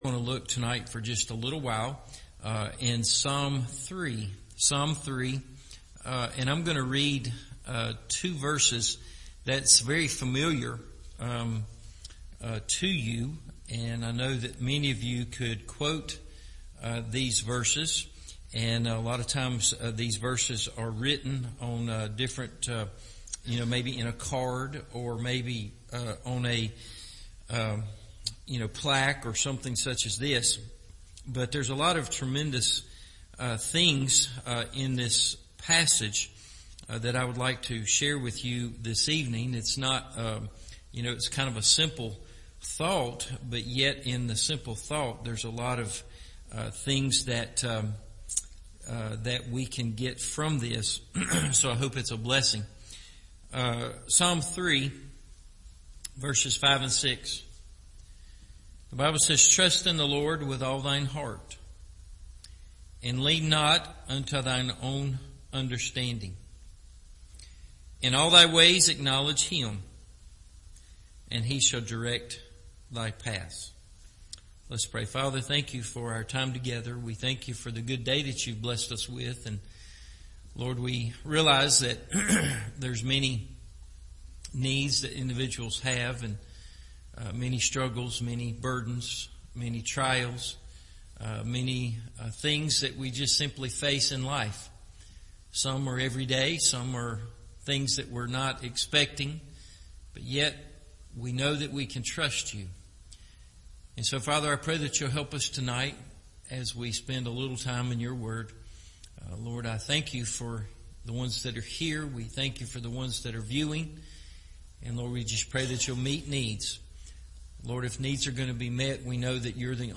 Guidance Along Life’s Pathway – Evening Service